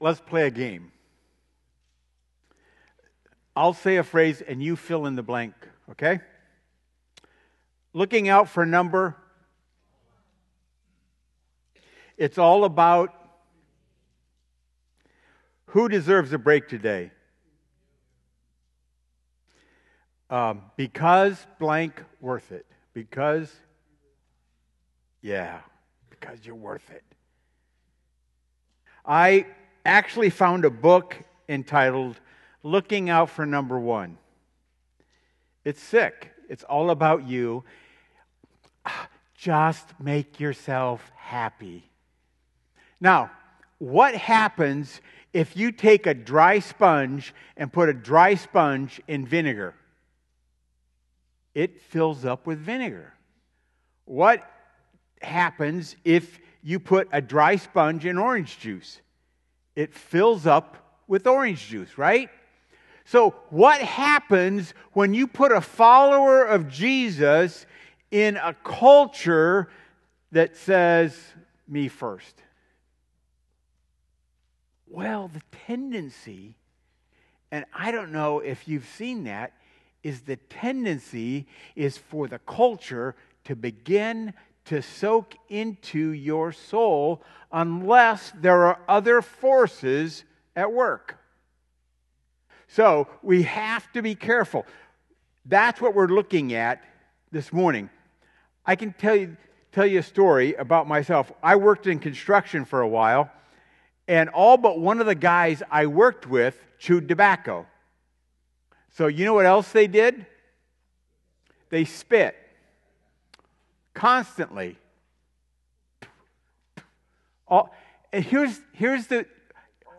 Sermons | Warsaw Missionary Church